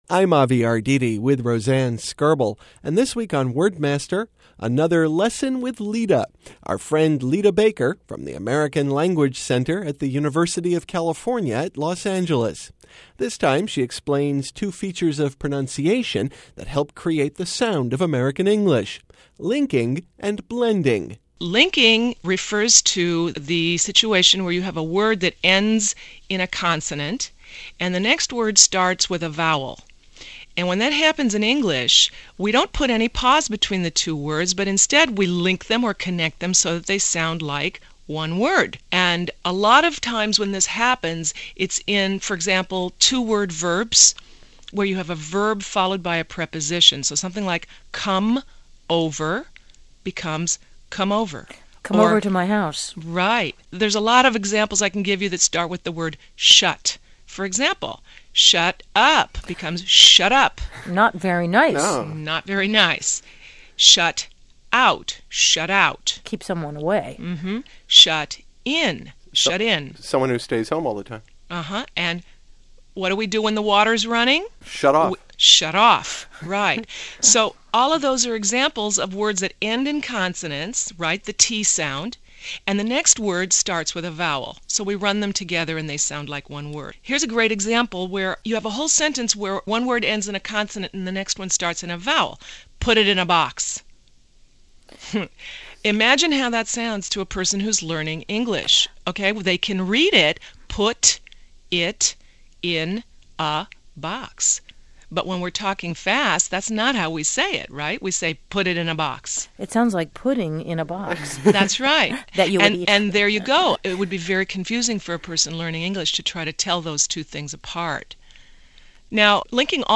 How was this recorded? First broadcast on February 20, 2003